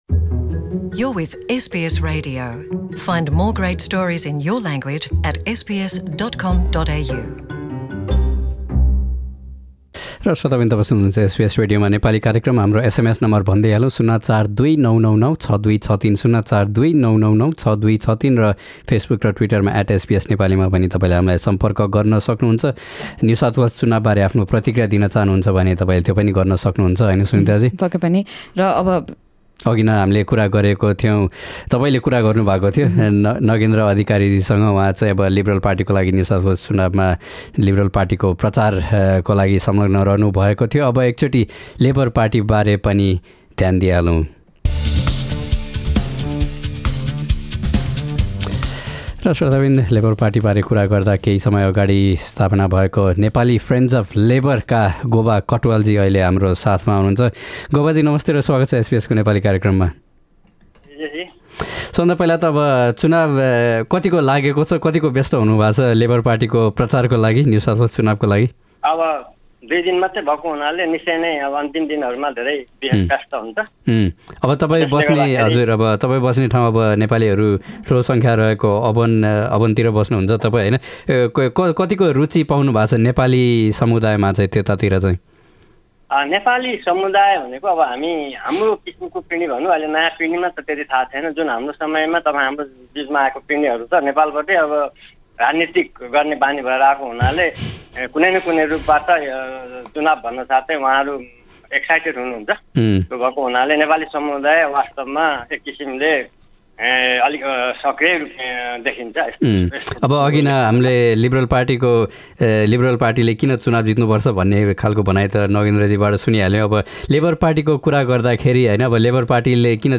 Nepali Friends of Labor member